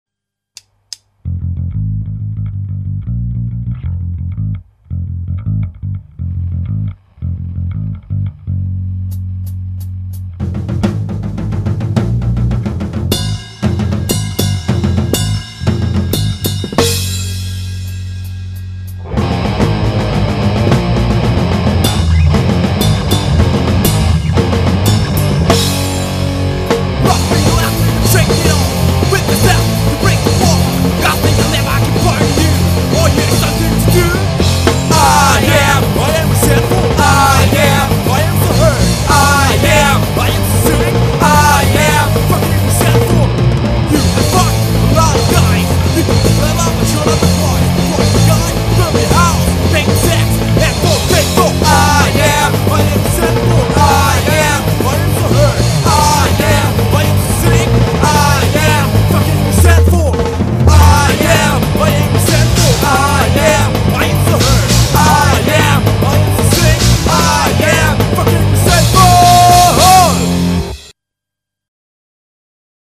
Hardcore-Ska-Band.